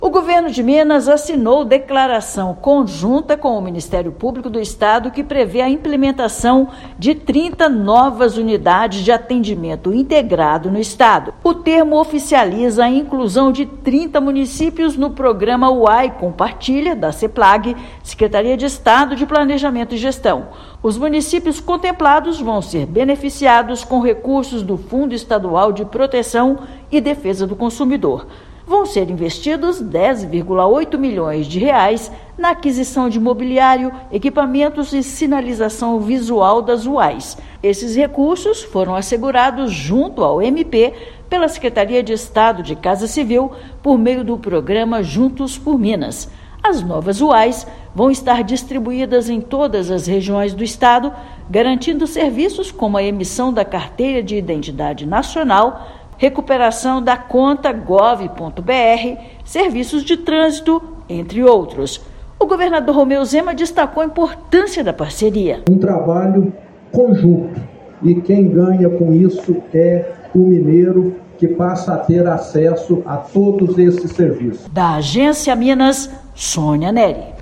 Termo assinado com o MPMG oficializa novas unidades, que serão viabilizadas por meio do Programa UAI Compartilha, da Seplag-MG. Ouça matéria de rádio.